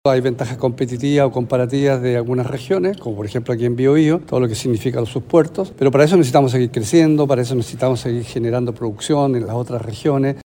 Fue el Salón Mural del Gobierno Regional el que albergó la reunión.